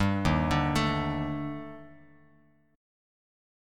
EbM#11 chord